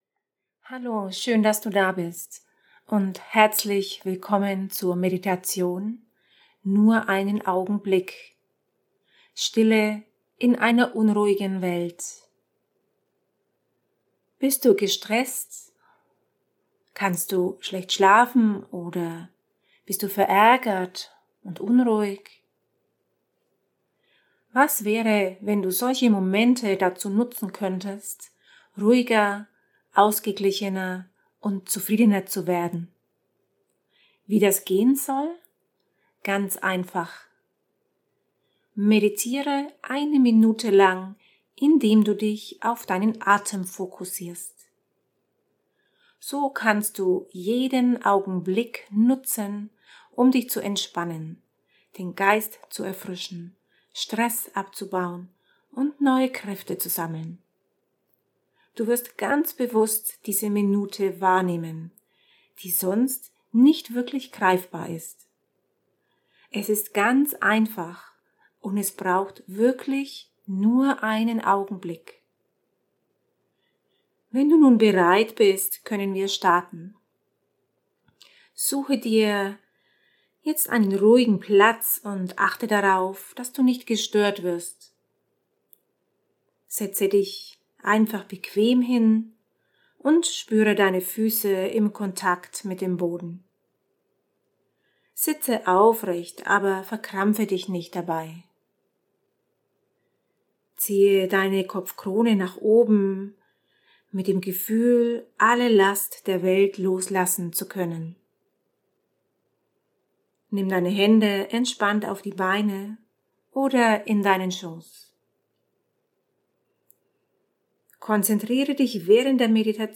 Audio - MEDITATIONEN
Dauer ca. 6 Minuten incl. kurzer Erklärung